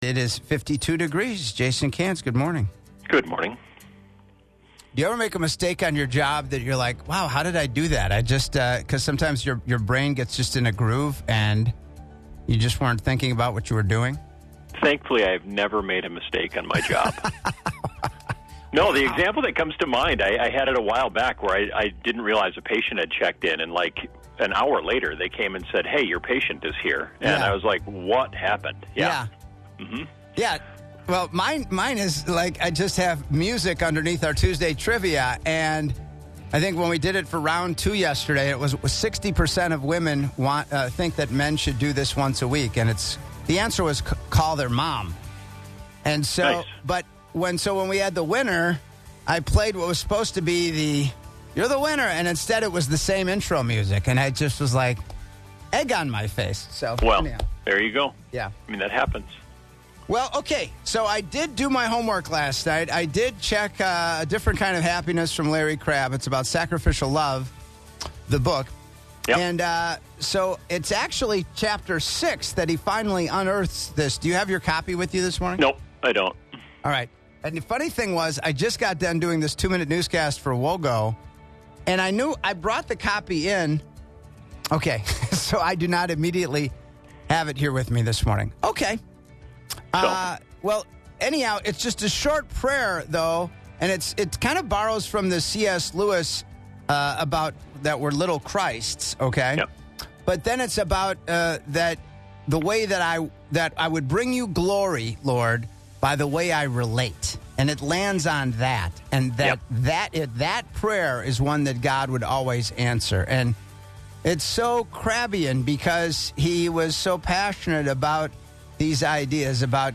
Audio from U.S. Senator, James Lankford(R-OK)
Inviting Your Mom-Honoring Voicemails